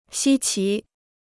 稀奇 (xī qí) Dictionnaire chinois gratuit